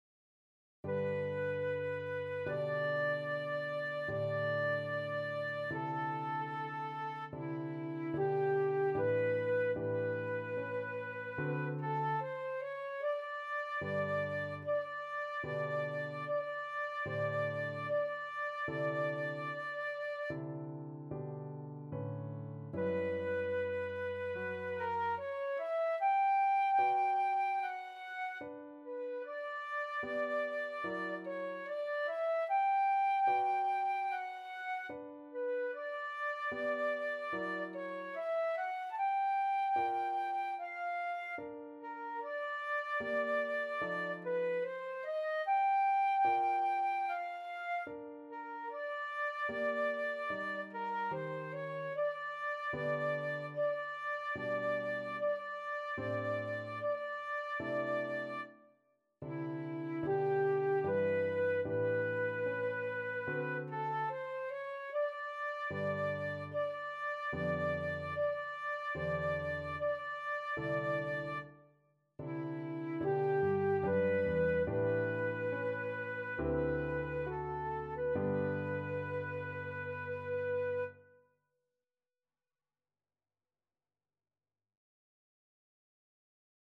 Flute version
Flute  (View more Intermediate Flute Music)
Classical (View more Classical Flute Music)